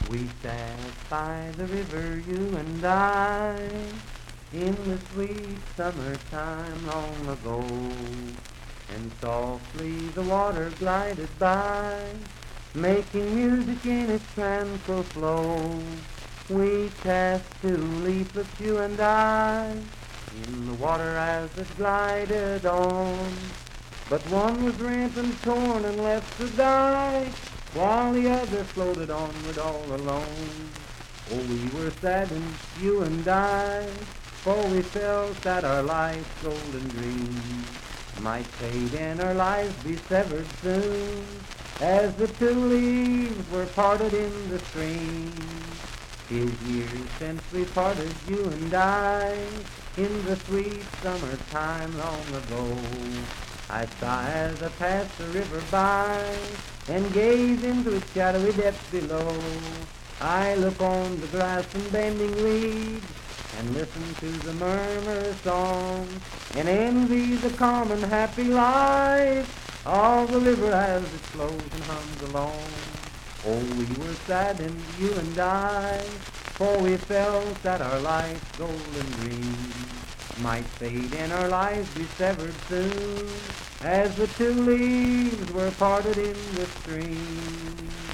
Unaccompanied vocal music
Voice (sung)
Wood County (W. Va.), Parkersburg (W. Va.)